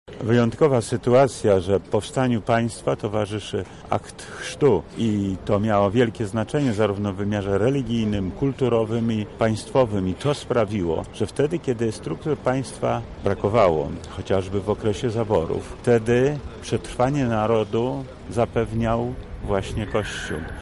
Lublin uczcił Święto Chrztu Polski.
• mówi Lech Sprawka, wojewoda lubelski.